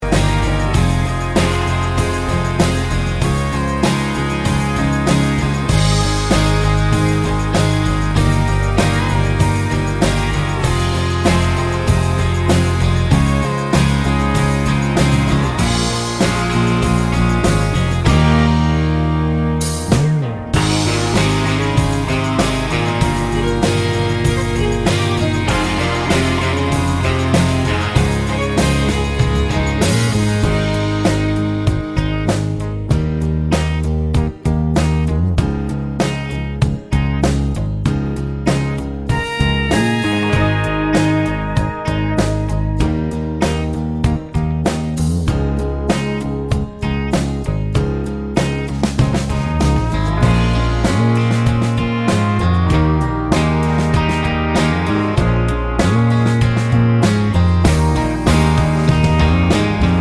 no Backing Vocals Country